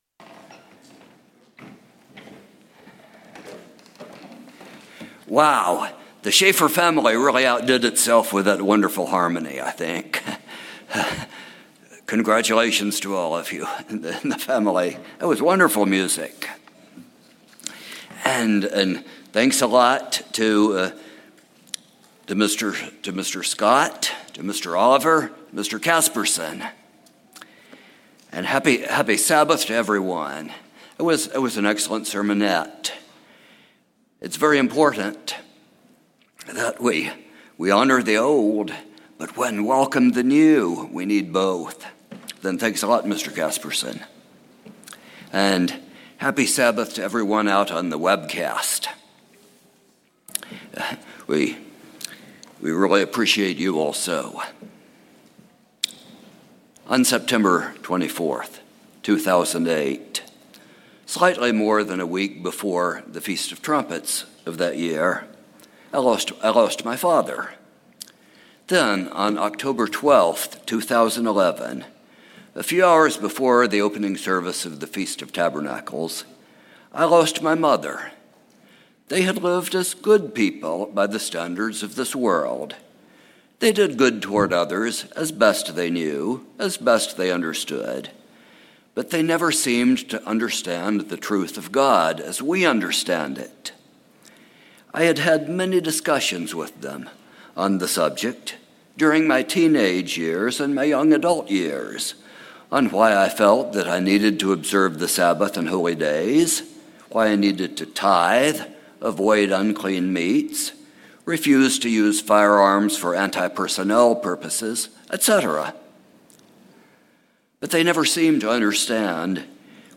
Given in Morehead City, North Carolina